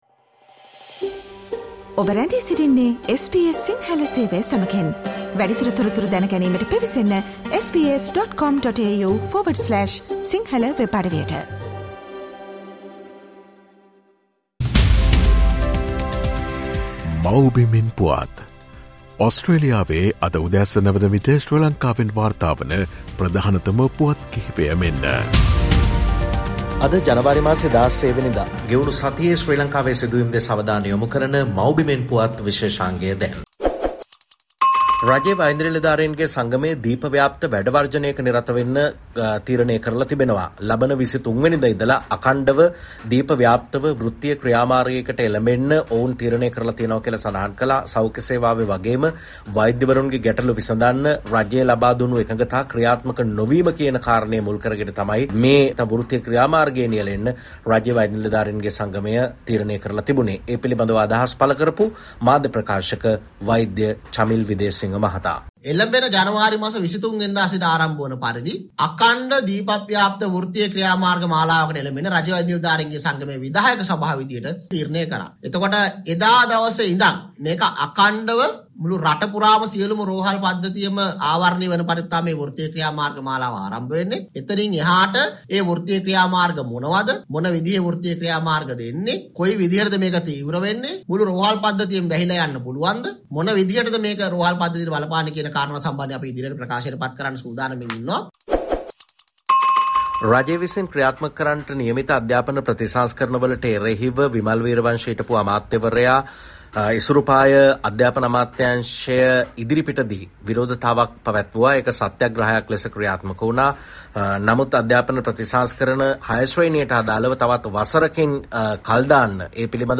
මේ සතියේ ශ්‍රී ලංකාවෙන් වාර්තා වූ උණුසුම් හා වැදගත් පුවත් සම්පිණ්ඩනය.